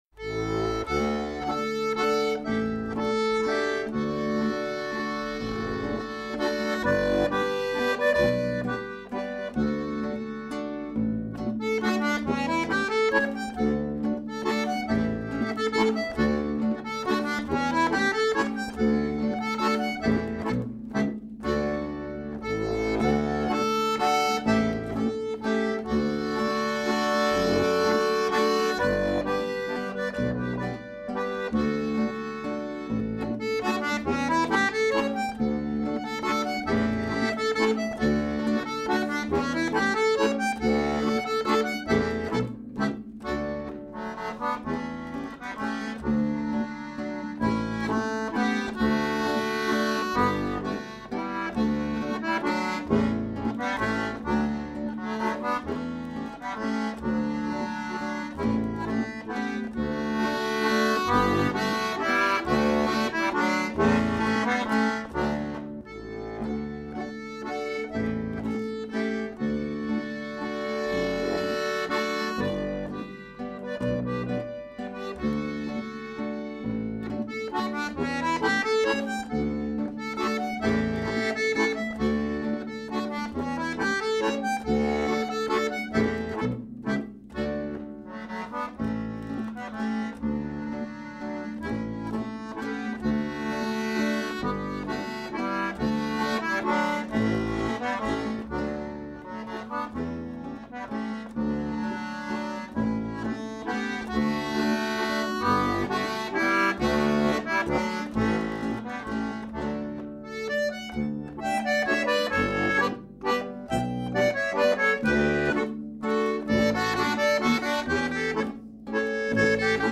Landler (Instrumental)